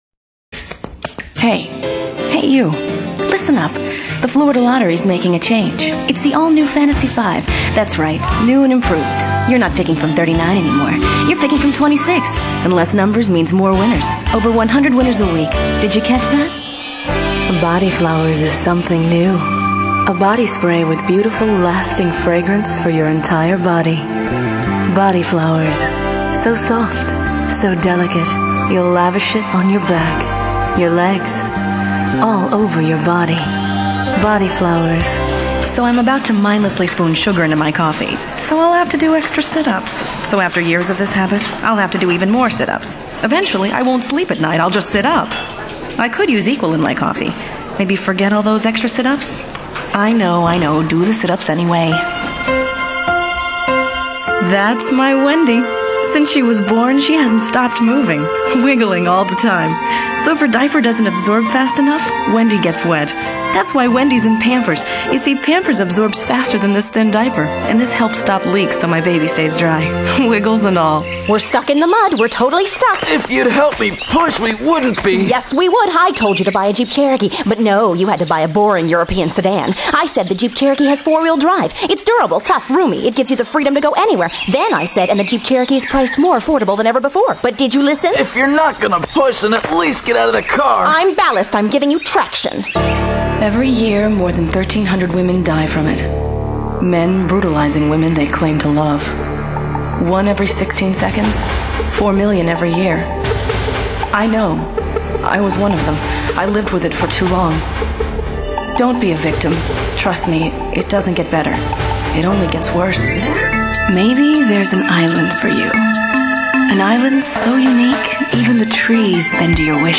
Commercial Announcing, Narrations, Television Promos, On-Hold Messaging
Radio Imaging, Character Voices, Automation for Multimedia